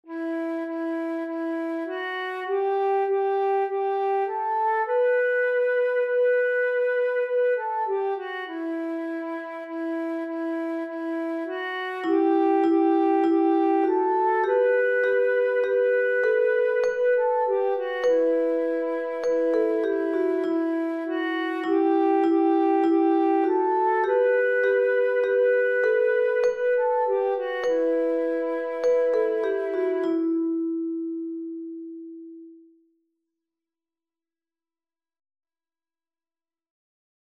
Eenstemmig of als canon te zingen